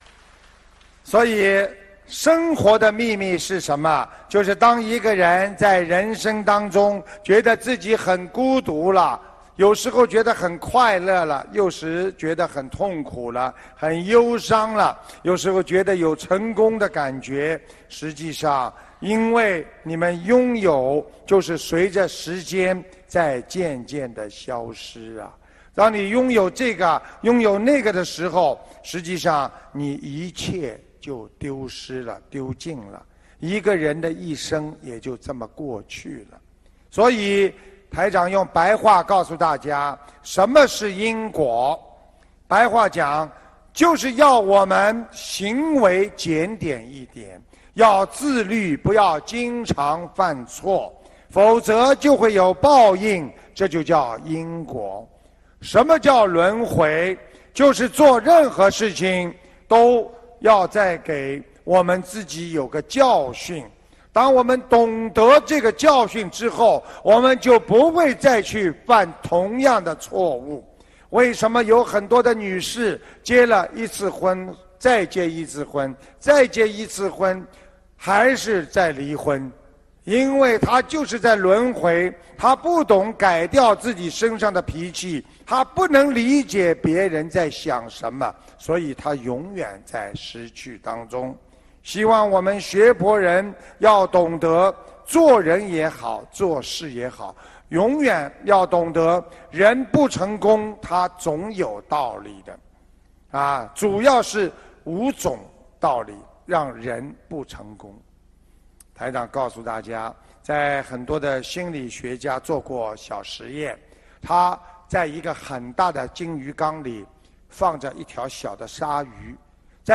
首页 >>菩提慧光 >> 法会小视频